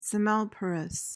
PRONUNCIATION:
(se-MEL-puh-ruhs)